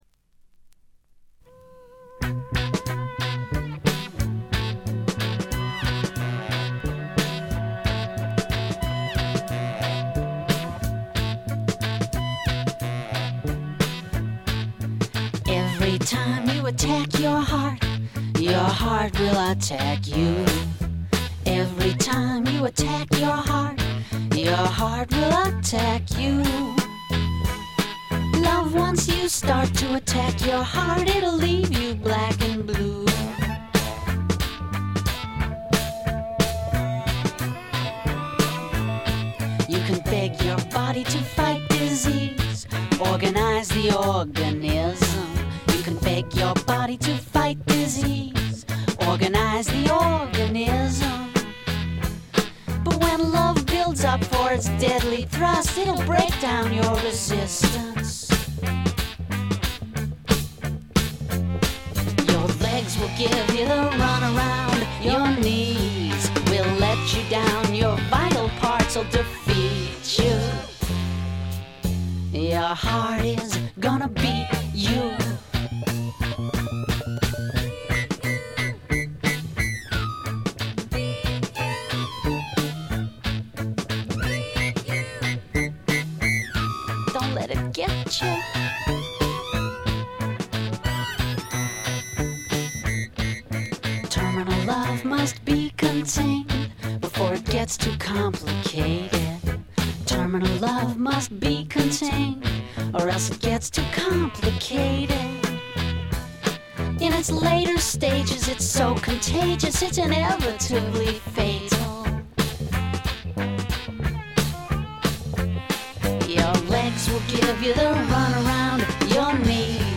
気付いたのはこれぐらい、ほとんどノイズ感無し。
試聴曲は現品からの取り込み音源です。